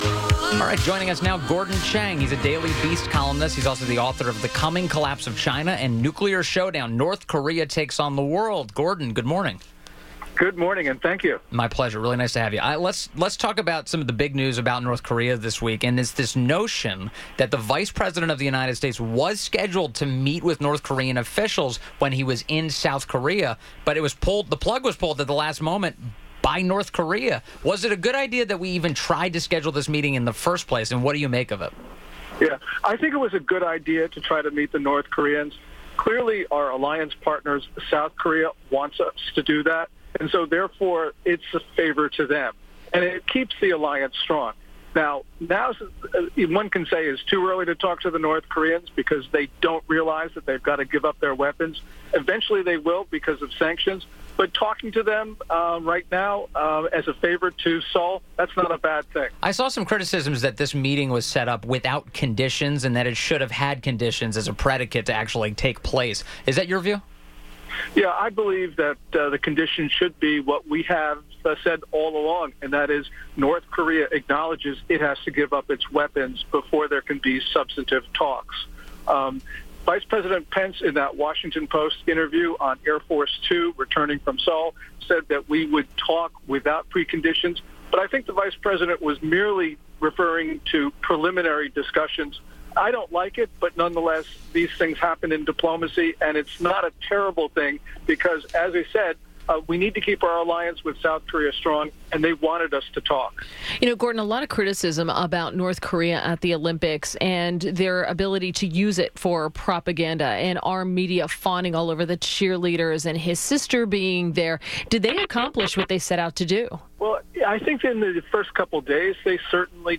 WMAL Interview - GORDON CHANG - 02.22.18